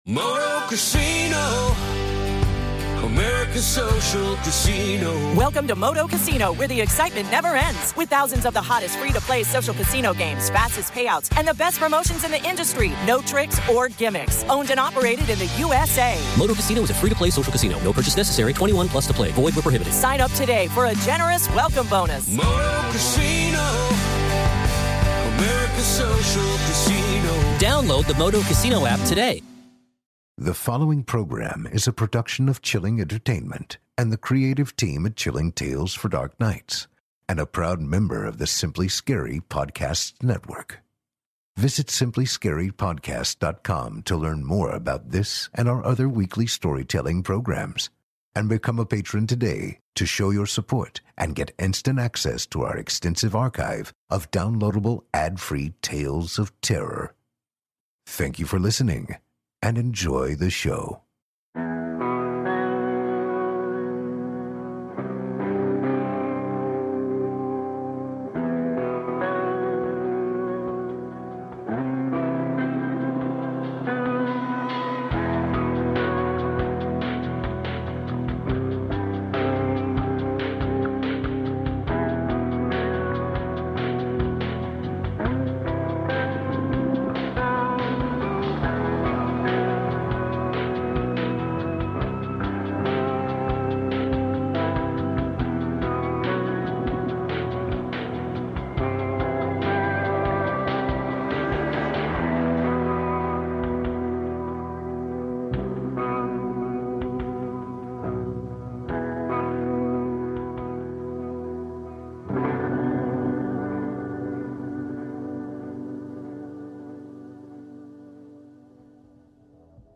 we’re going to be reading two stories, both dealing with forces outside of our natural world.